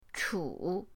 chu3.mp3